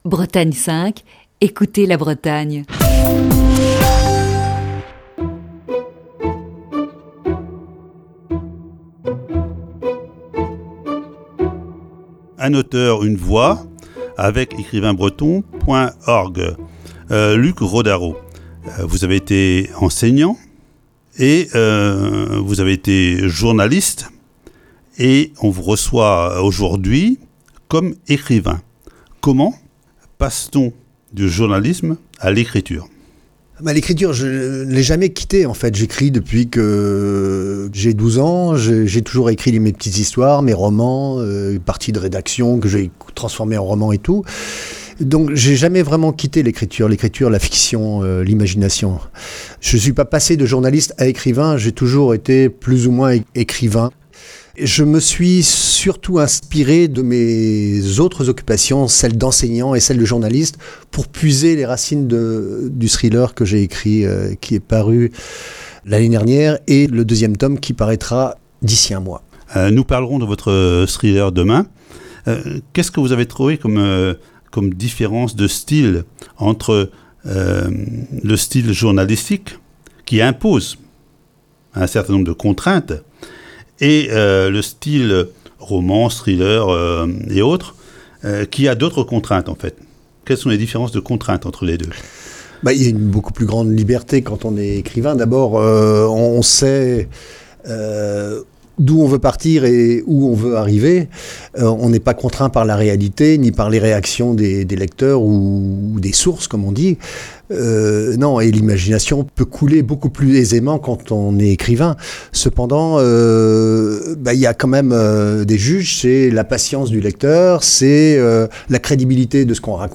Voici ce mercredi, la troisième partie de cette série d'entretiens.